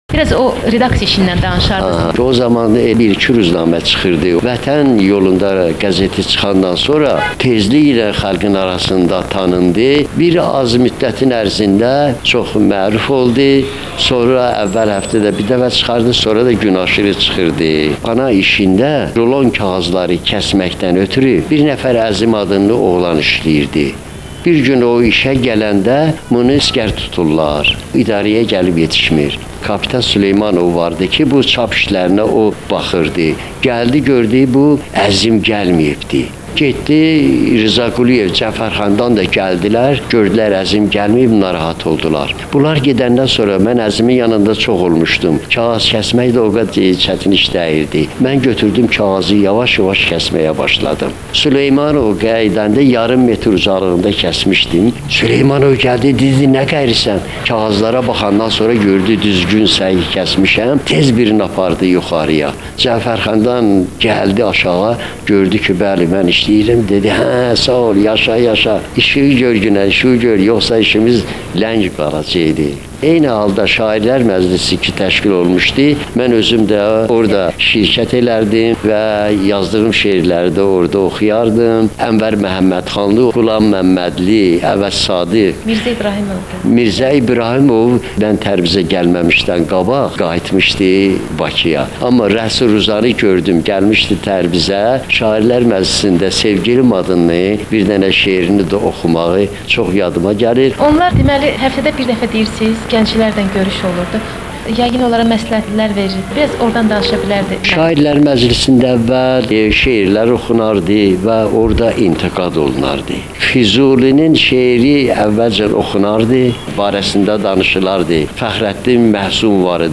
مصاحیبه / اودلار یوردو رادیوسو